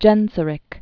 (jĕnsə-rĭk, gĕn-) also Gai·ser·ic (gīzə-) Died AD 477.